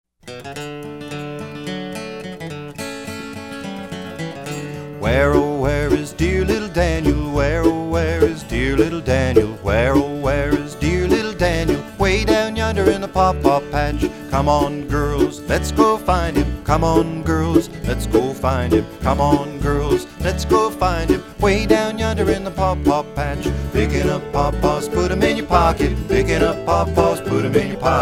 This collection of folk song favorites